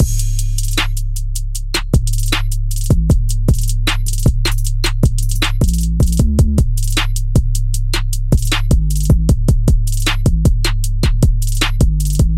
鼓的步骤 鼓的循环
描述：鼓的步骤鼓的循环
Tag: 172 bpm Drum And Bass Loops Drum Loops 1.88 MB wav Key : Unknown